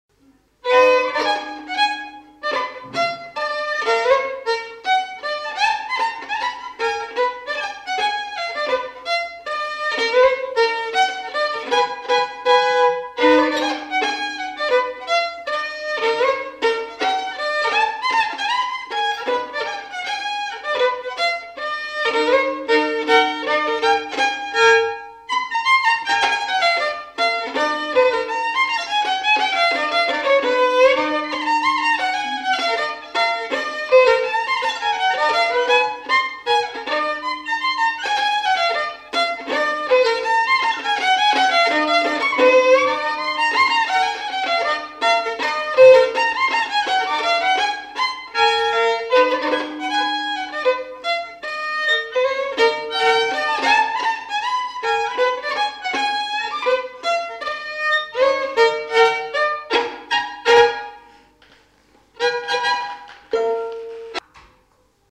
Aire culturelle : Lomagne
Lieu : Garganvillar
Genre : morceau instrumental
Instrument de musique : violon
Danse : scottish